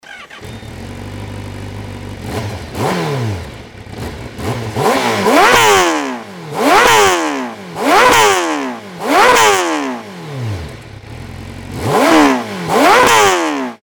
アクラポヴィッチ製チタンマフラーの空拭かし音を
CBR1000RR-Rの純正マフラー（メーターパネル前で収録）
間違いなく純正マフラーとしては過去最高に爆音です。
バーーーーーーからパーーーーーンに排気音が変化して